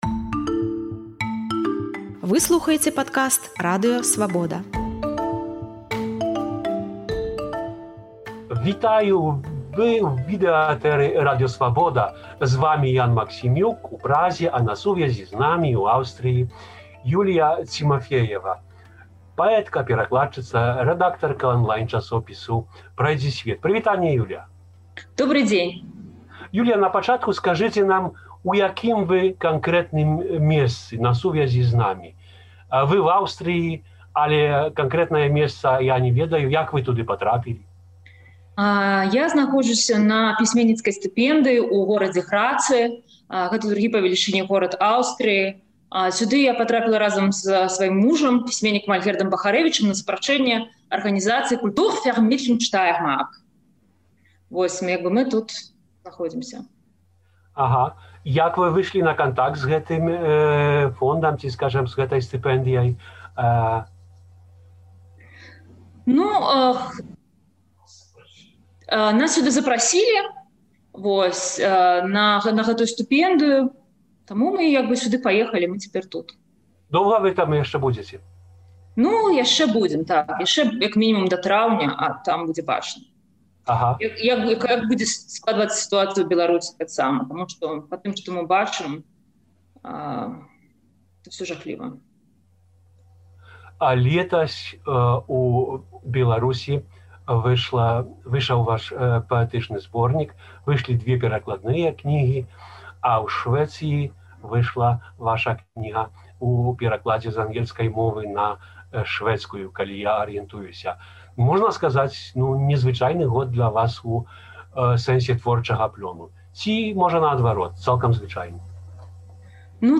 гутарыць з паэткай і перакладчыцай